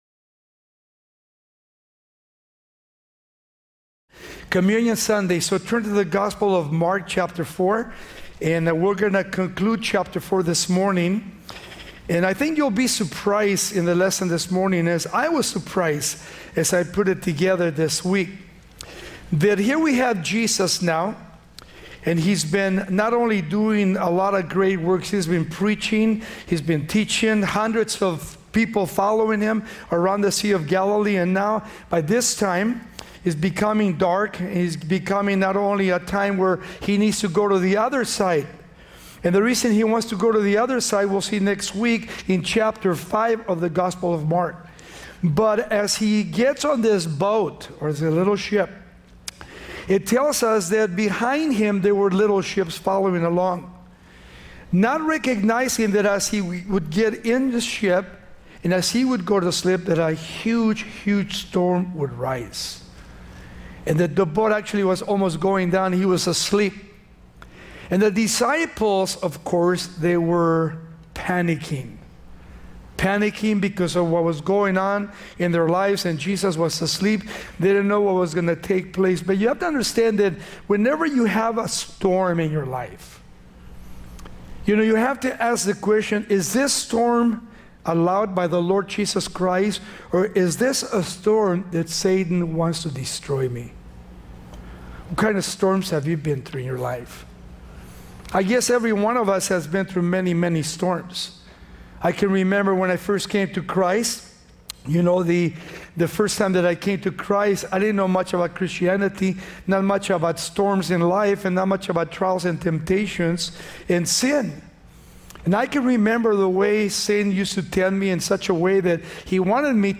Each series type is a verse by verse Bible study, rotating from each Testament upon completion.